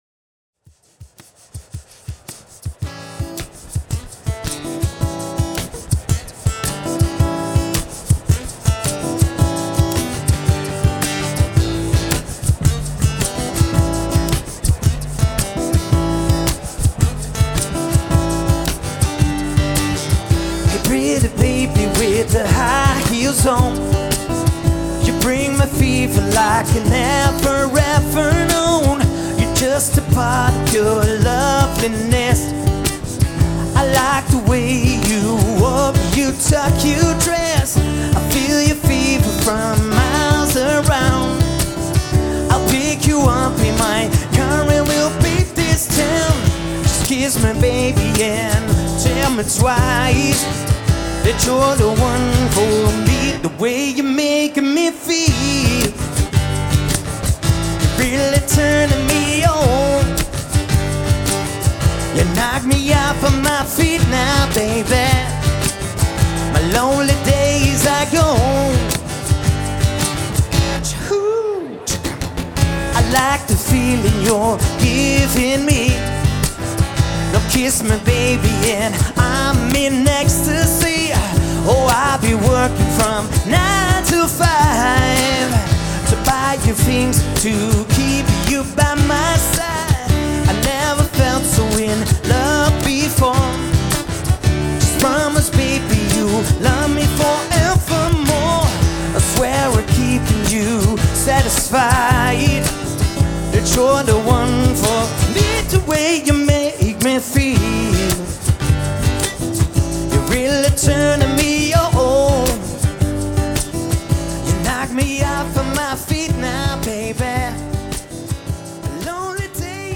one man band